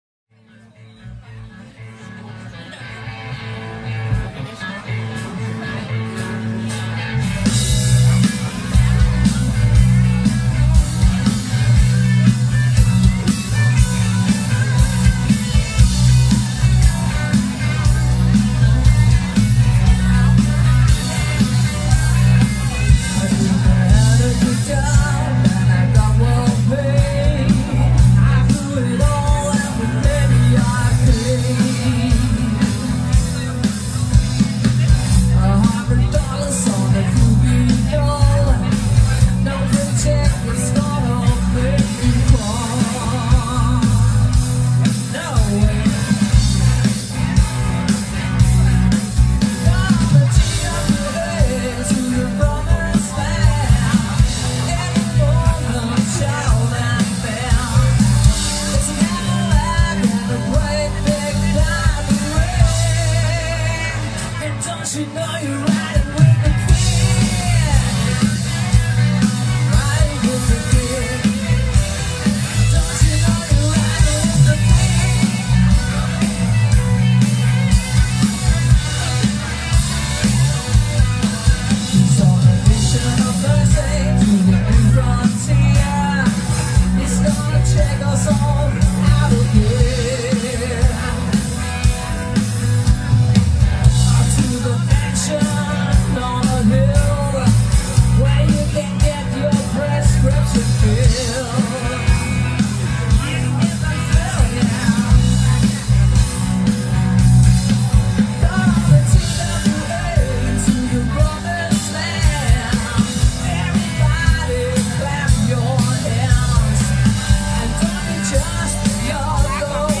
Indspillet Live 3. juni 2024.